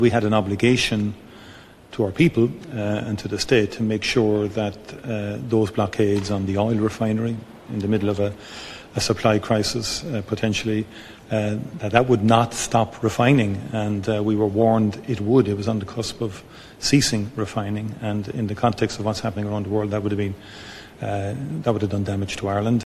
Micheal Martin says the Government has a responsibility to its people……………